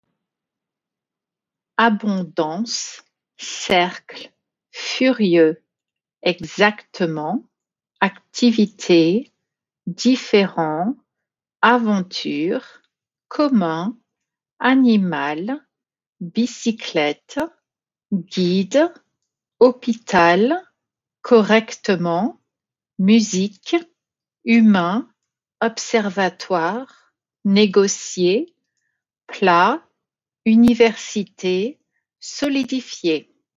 The biggest difference between these French and English words is their pronunciation, even when they are spelled the same.